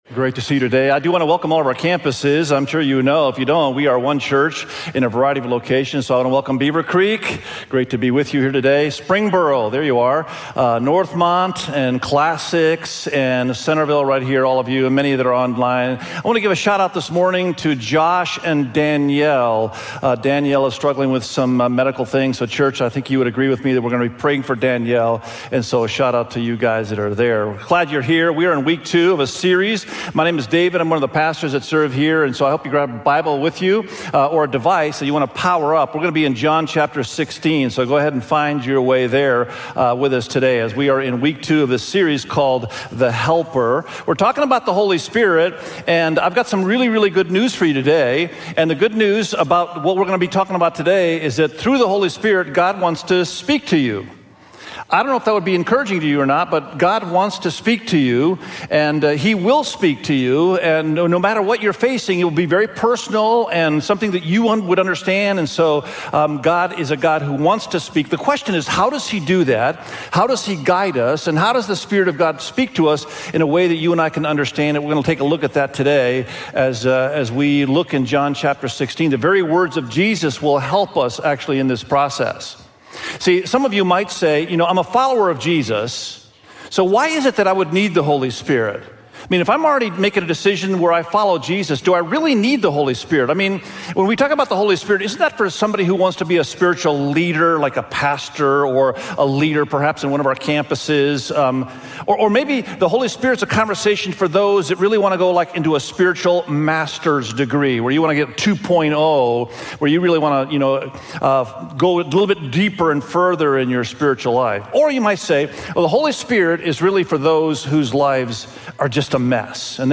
God-Speaks_SERMON.mp3